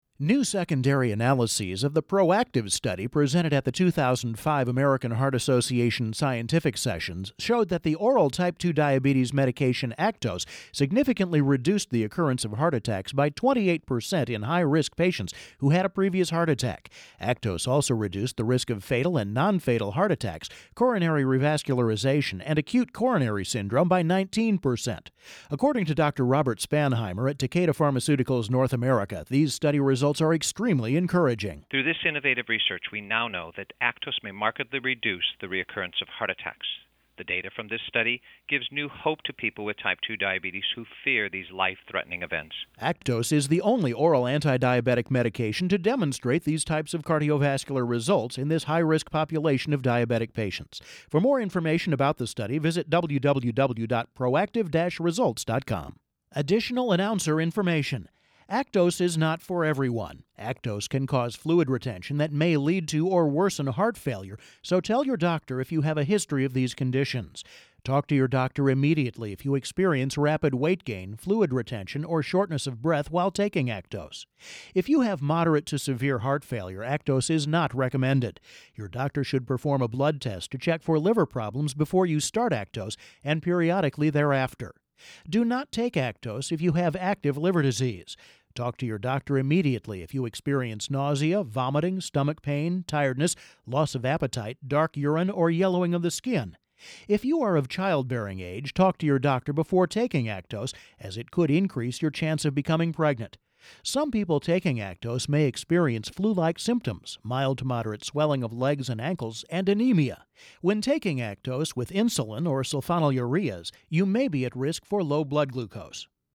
Click here to hear an ACTOS audio news release